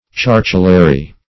Chartulary \Char"tu*la*ry\, n.